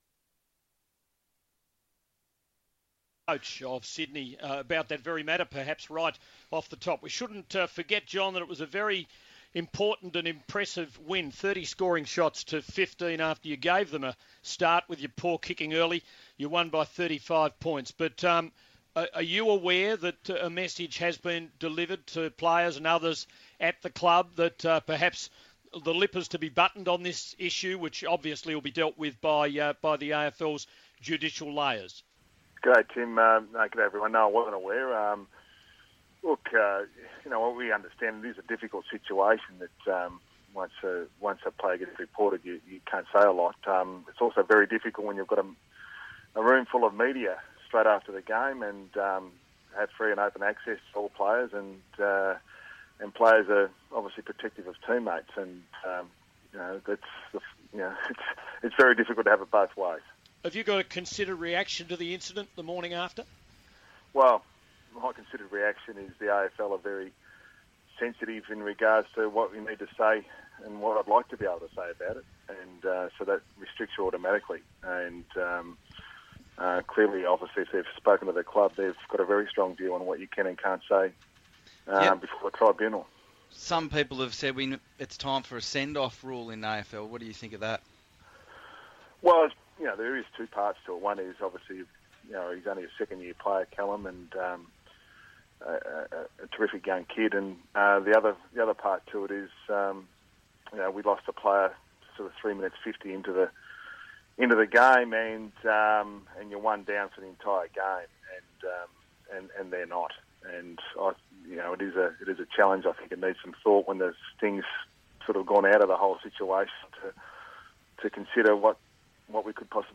John Longmire has his say on Melbourne's 3AW Radio following Friday night's win over Melbourne.